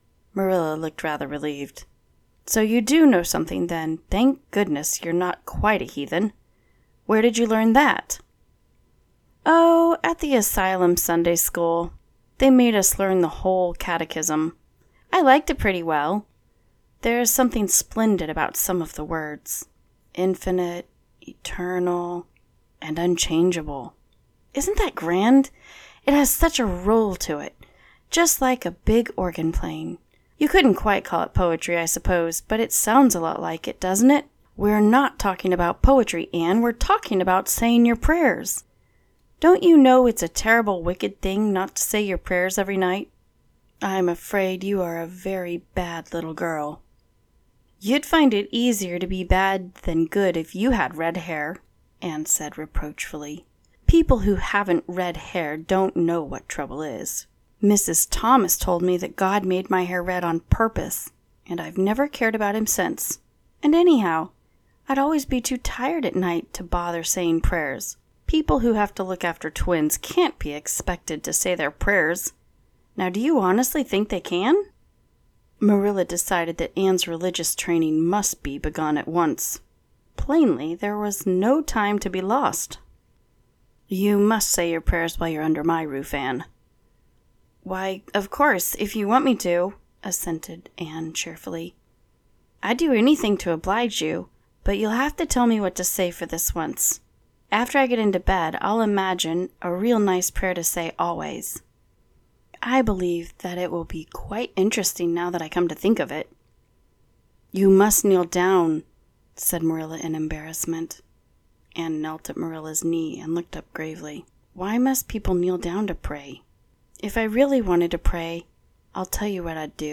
I have been told that my voice is nice to listen to!
3-minutes-anne-of-green-gables-sound-bite.mp3